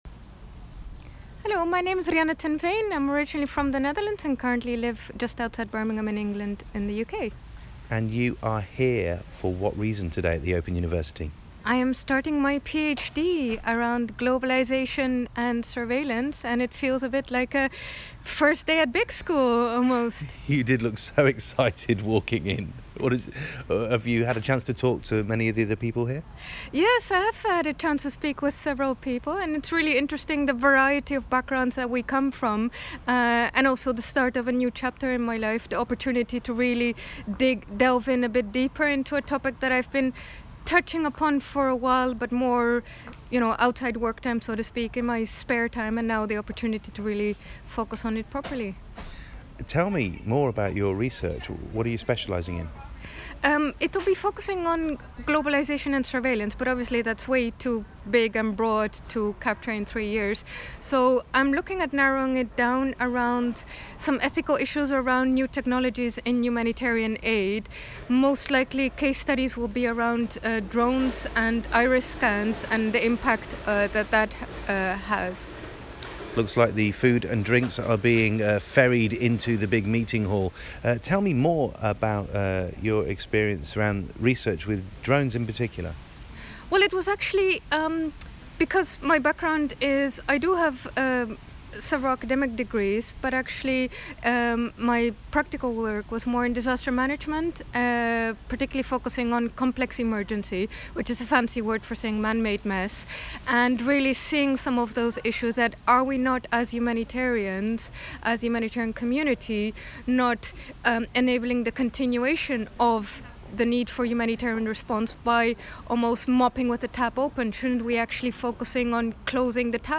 chats with me at the Open University about drones, retinal scanning of refugees and disaster response.